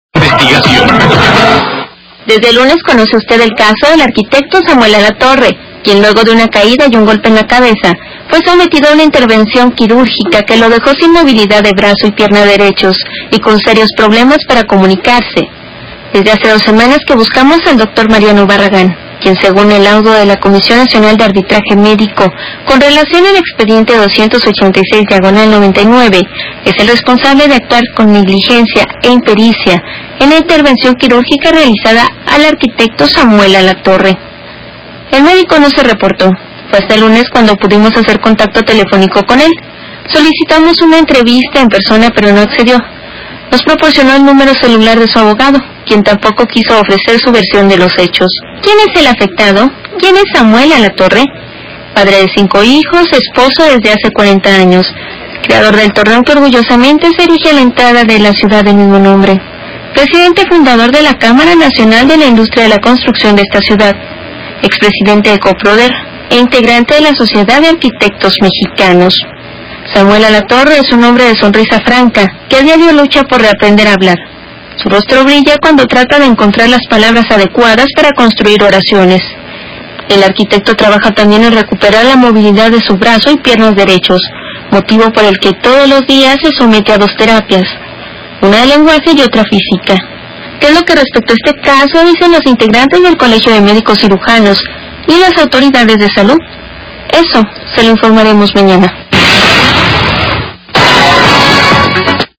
R A D I O